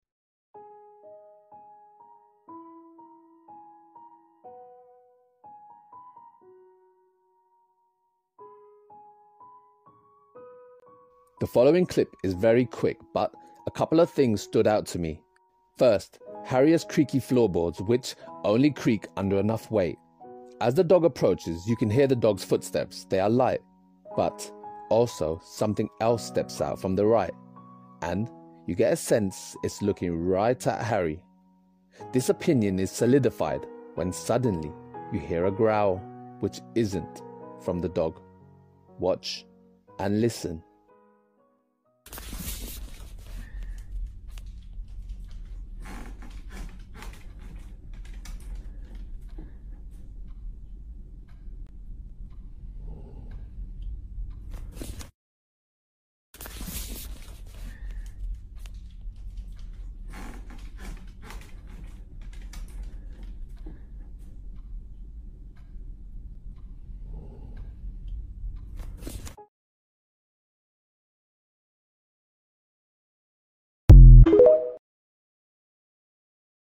Poltergeist activity. heavy footsteps and growls.